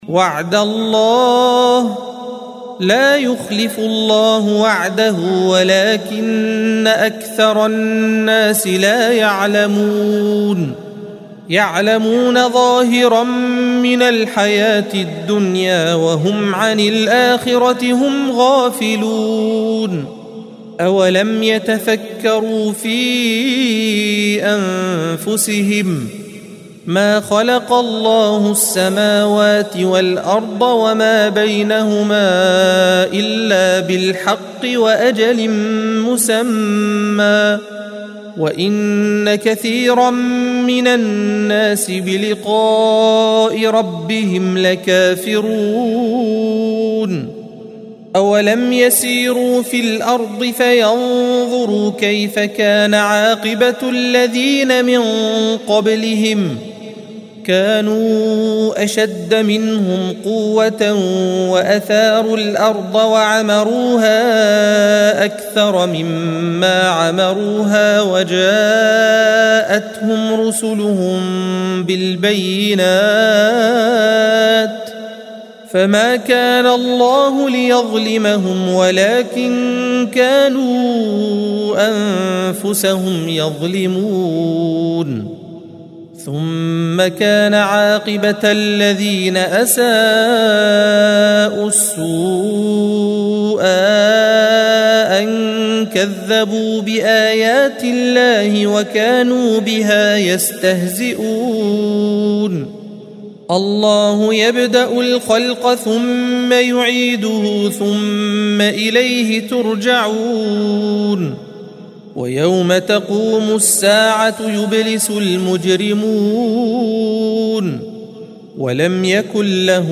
الصفحة 405 - القارئ